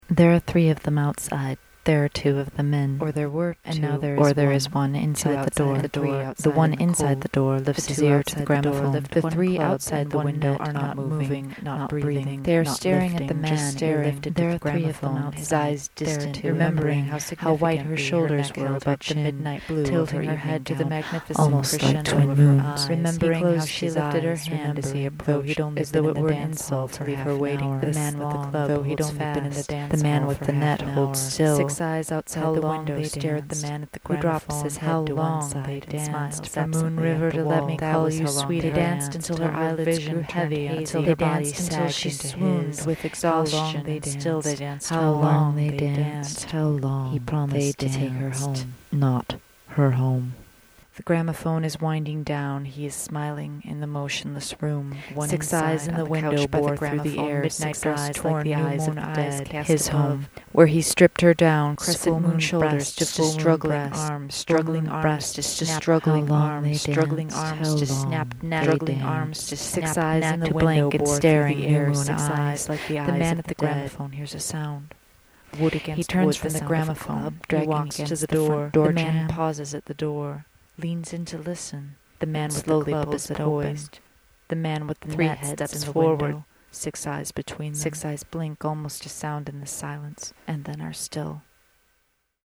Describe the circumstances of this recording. this monday we're rocking the boat a bit. expect to review this a few times, at least. think headphones: i'm in stereo!